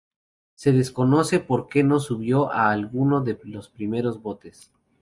al‧gu‧no
/alˈɡuno/